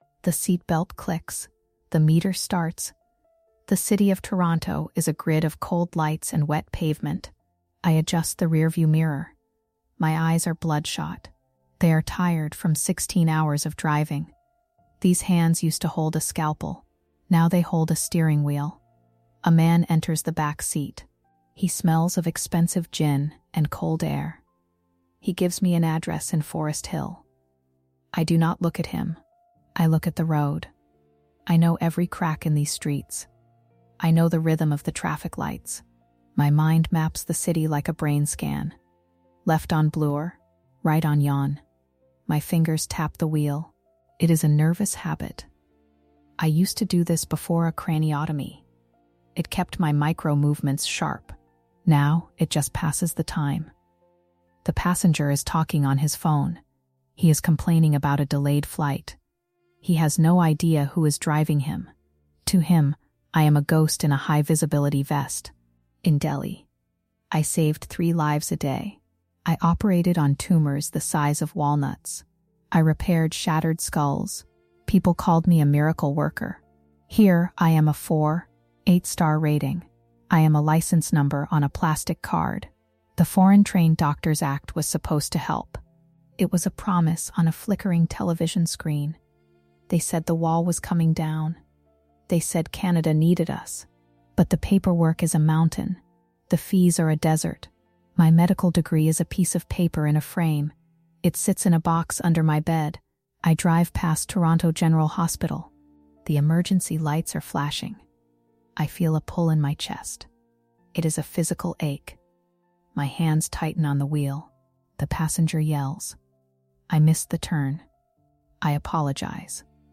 Experience a gripping, first-person medical drama in this episode of THE TRIALS OF WOMAN titled "The Credential Wall." Set against the backdrop of the 2025 Foreign-Trained Doctors Act, this story follows the harrowing reality of a world-class neurosurgeon from Delhi who finds herself navigating the cold streets of Toronto as a taxi driver.
Perfect for fans of high-stakes storytelling, social justice narratives, and medical thrillers, this TTS-optimized script delivers a raw, grounded look at the moral compromises made in the shadows of the healthcare system.